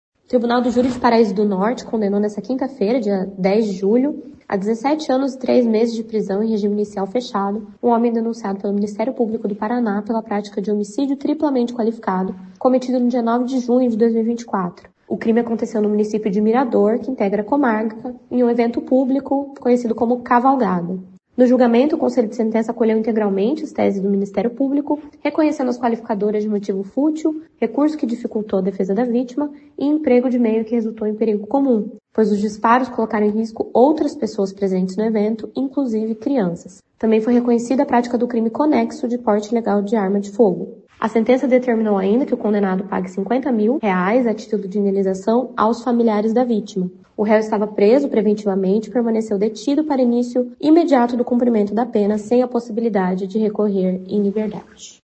Ele também foi condenado por porte ilegal de arma de fogo, como explica a promotora Bruna Britto Martins.